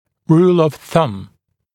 [ruːl əv θʌm][ру:л ов сам]правило большого пальца